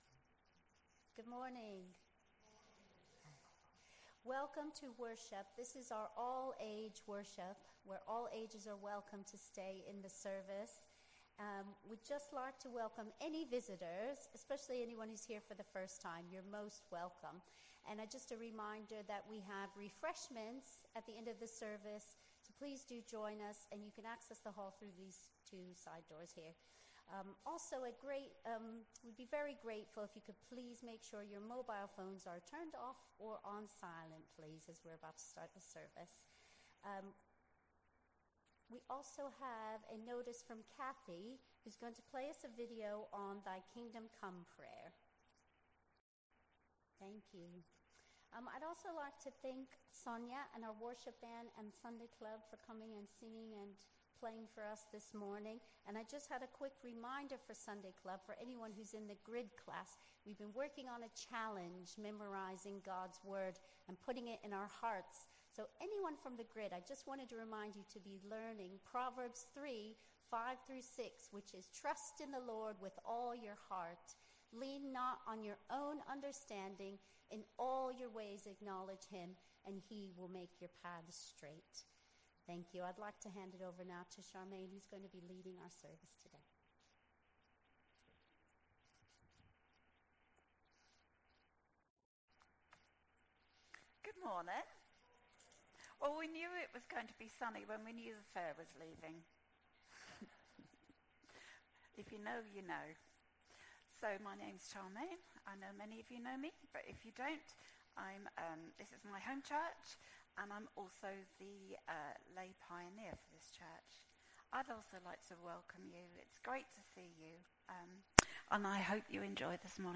An audio version of the service is also available.